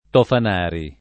[ tofan # ri ]